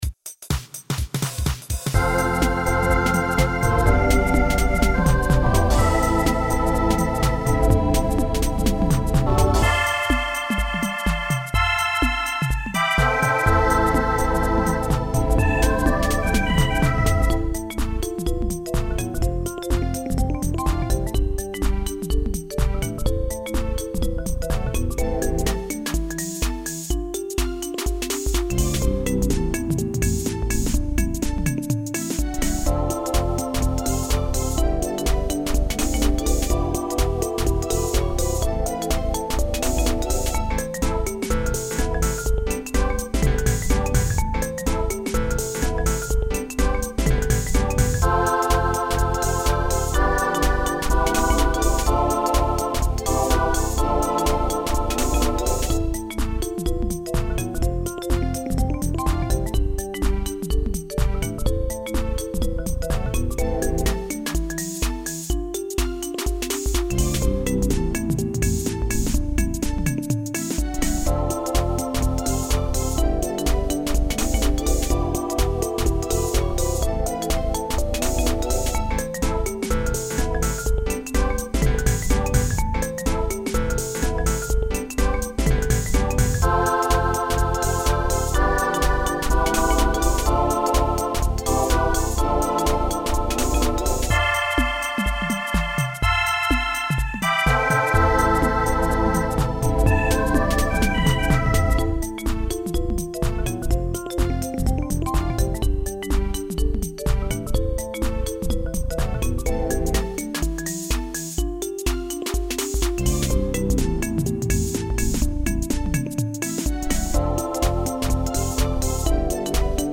オリジナル(インスト)